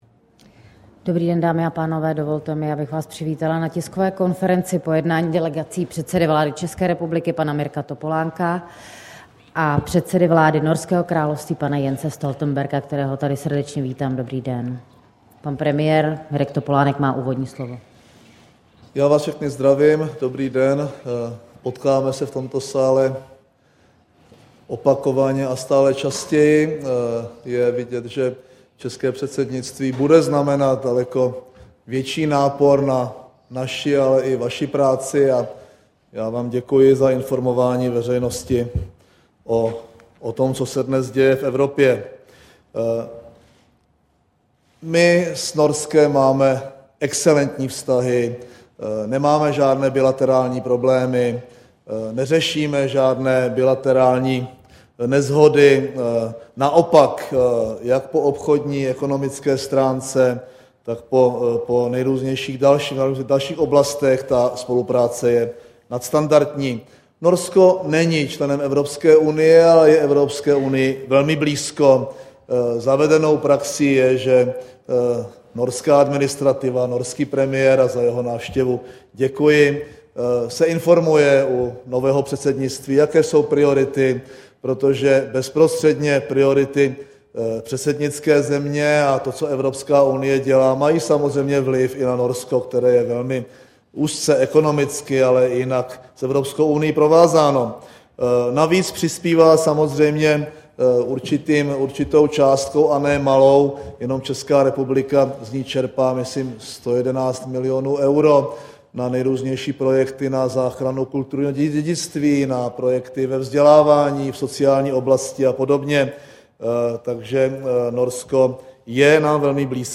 TK - Premiér Mirek Topolánek a norský premiér Jens Stoltenberg